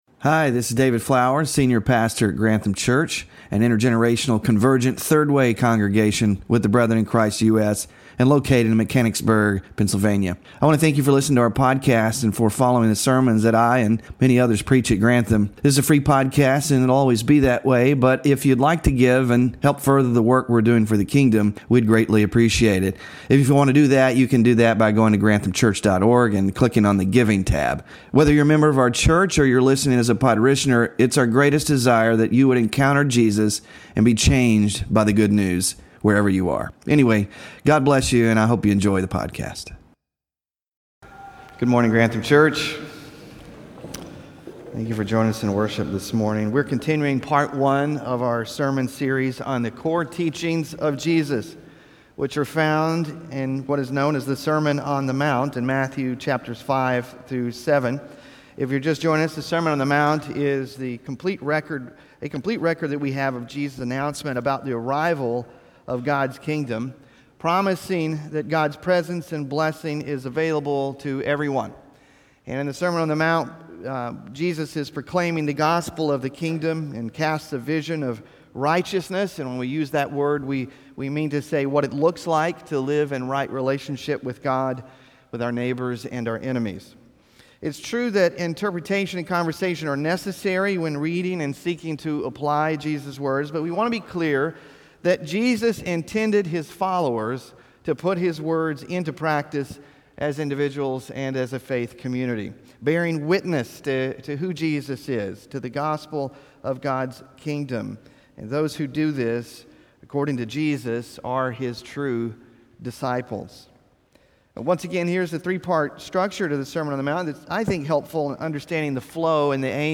Scripture Reading: Matthew 5:17-20; 7:12; 22:36-40